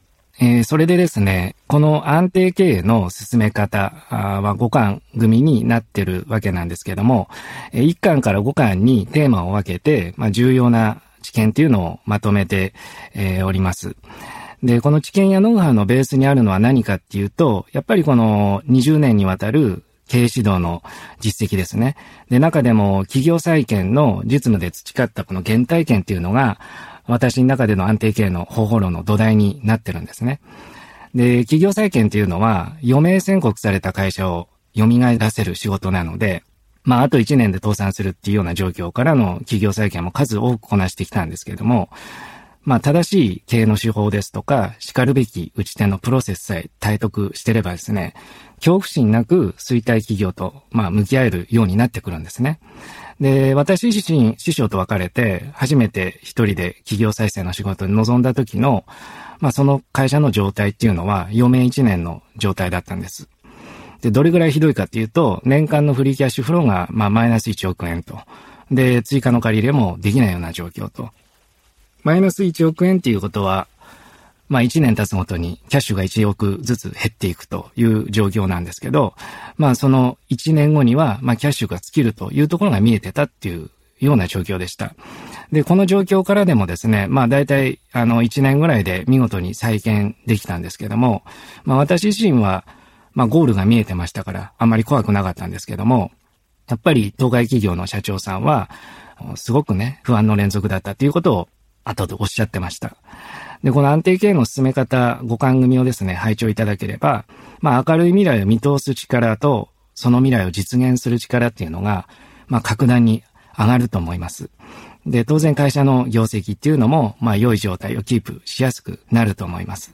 「安定経営のすすめ方」講話のサンプル音声をお聴きいただけます。